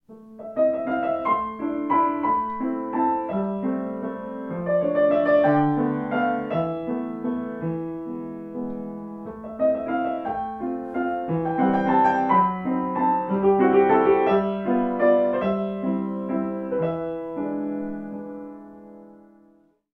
Klavierkonzerte solo